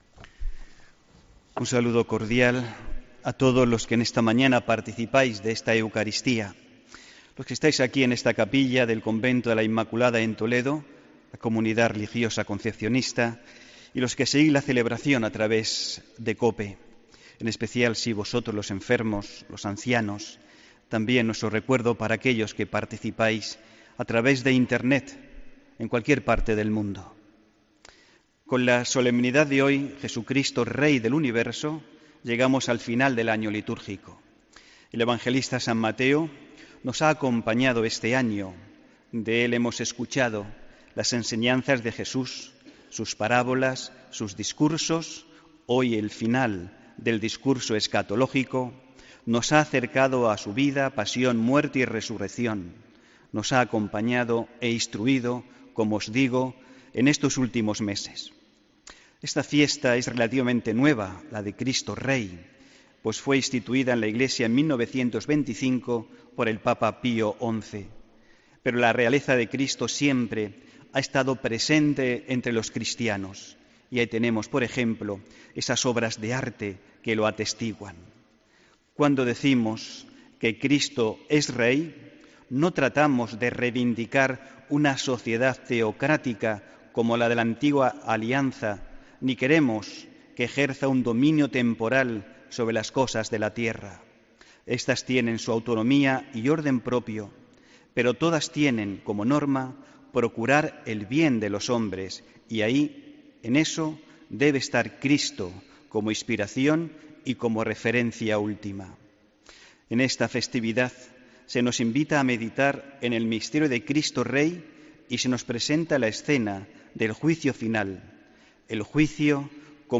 HOMILÍA 26 NOVIEMBRE 2017